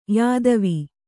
♪ yādavi